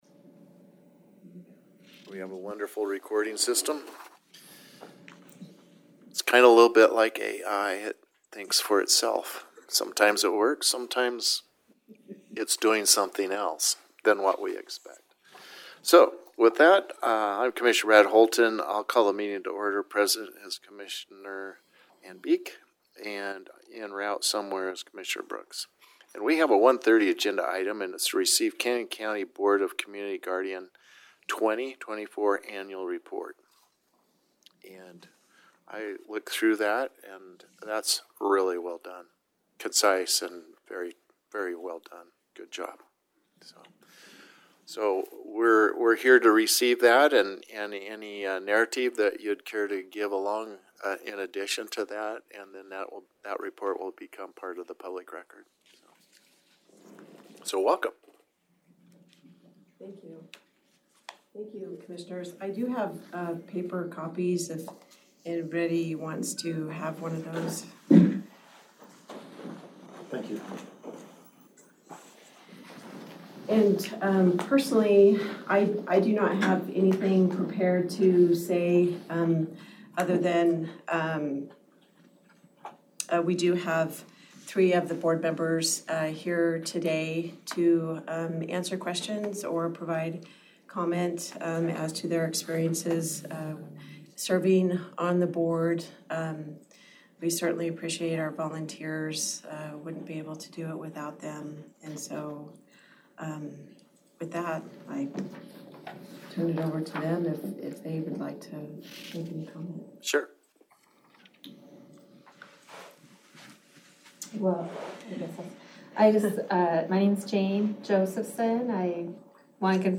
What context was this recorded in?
During a recent Canyon County Board of Commissioners meeting, the BOCG presented its 2024 Annual Report, highlighting the challenges and triumphs of the program.